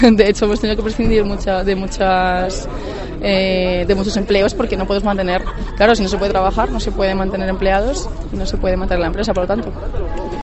profesora de autoescuela